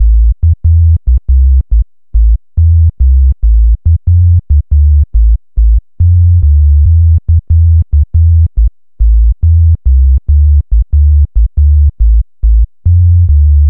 Exodus - Bass.wav